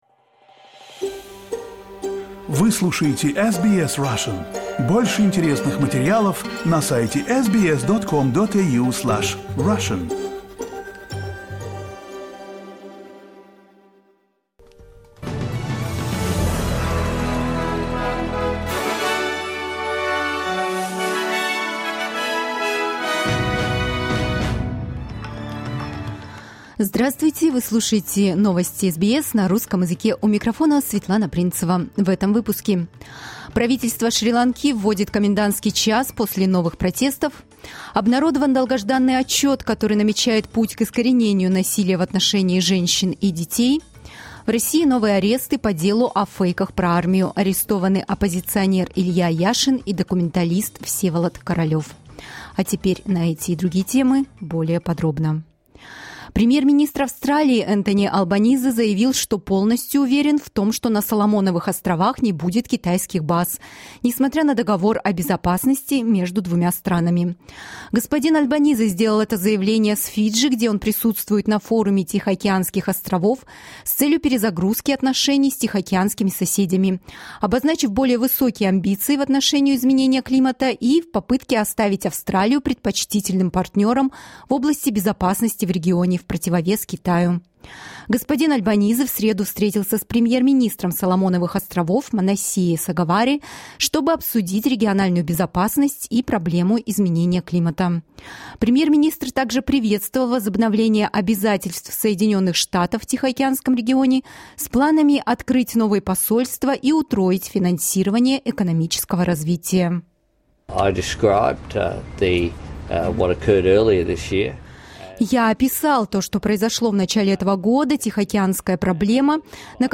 Listen to the latest news headlines in Australia from SBS Russian.